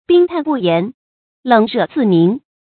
bīng tàn bù yán, lěng rè zì míng
冰炭不言，冷热自明发音